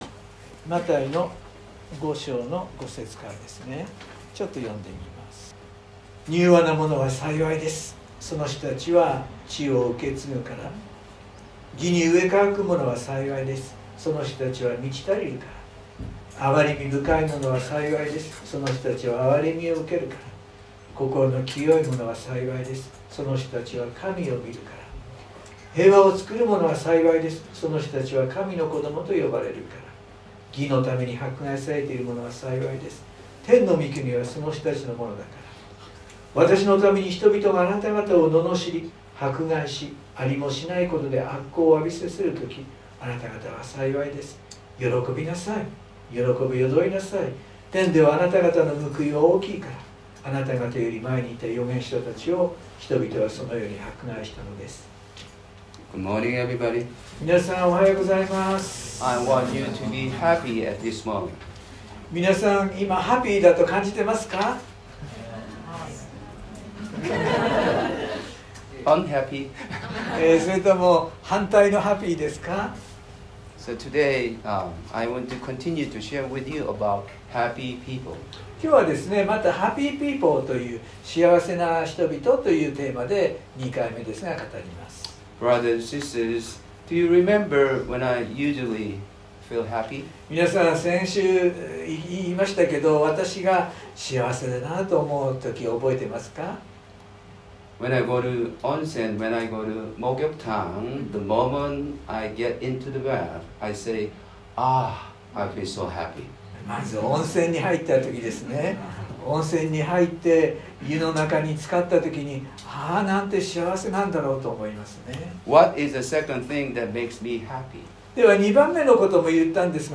（日曜礼拝録音） 【iPhoneで聞けない方はiOSのアップデートをして下さい】 原稿は英語のみになります。
(If you can’t hear from the bar above, click the blue button) iPhone 宣教師メッセージ 礼拝メッセージ シェアする X Facebook はてブ LINE コピー インターナショナル・バイブル・フェローシップ